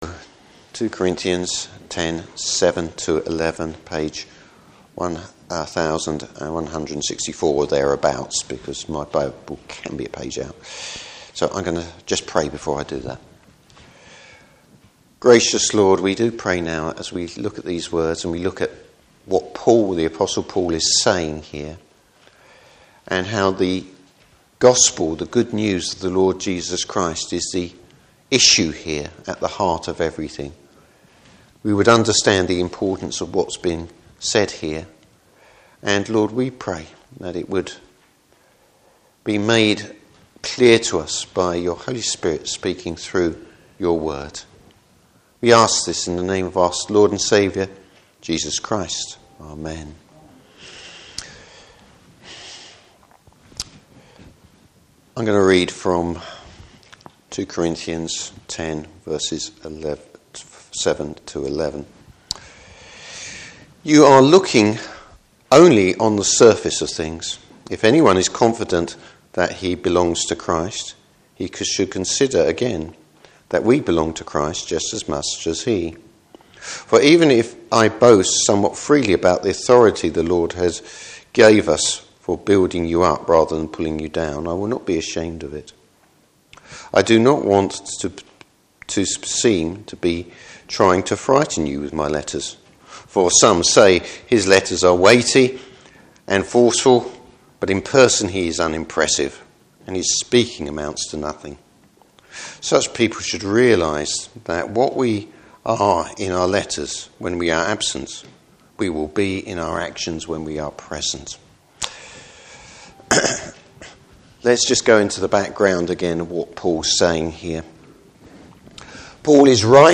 Service Type: Morning Service Place places his confidence in the Lord.